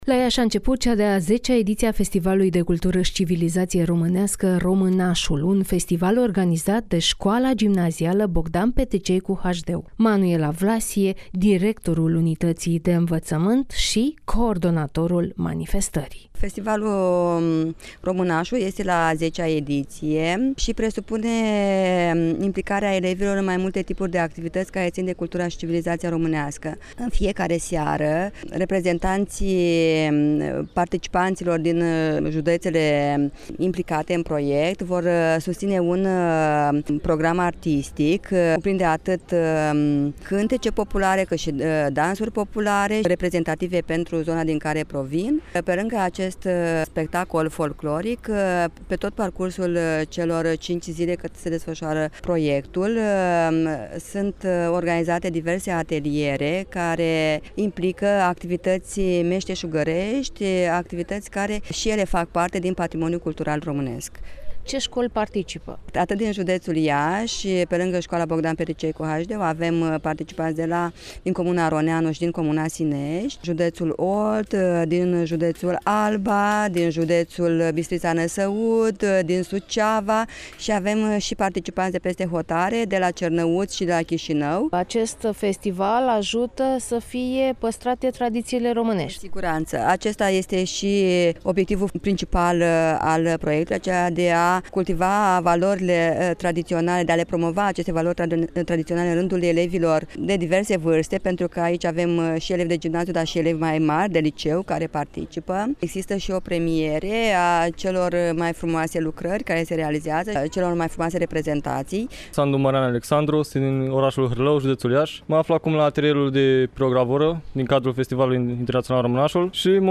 (REPORTAJ) A început cea de-a X-a ediție a Festivalului de Cultură și Civilizație Românească „Românașul”